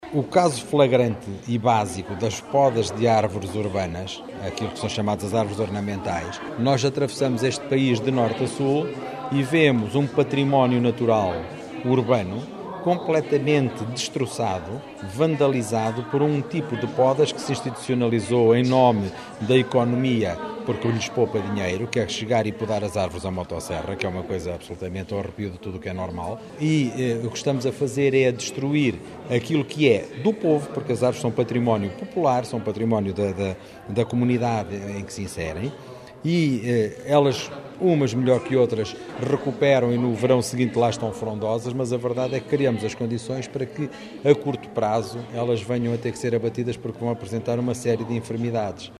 Declarações à margem do 7º Encontro de Boas práticas ambientais que terminou ontem e durante dois dias reuniu especialistas nacionais e internacionais na à área do ambiente, no Instituto politécnico de Bragança.